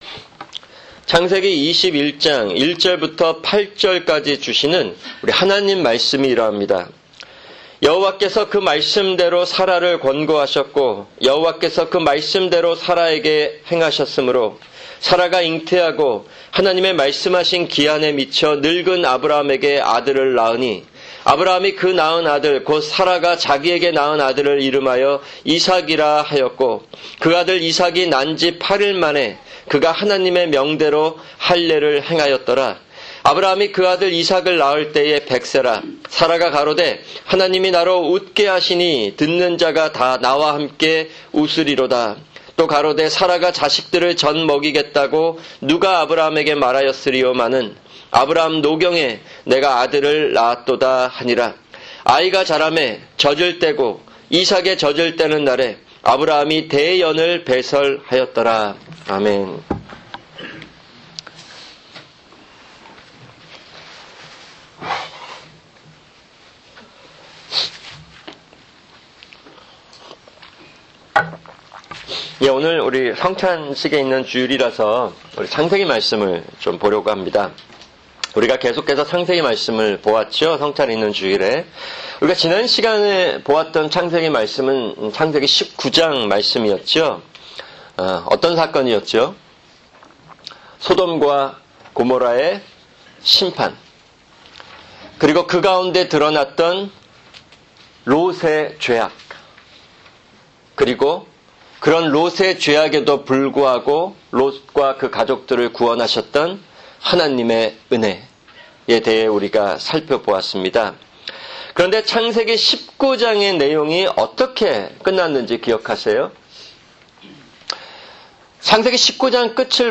[주일 설교] 창세기 21:1-8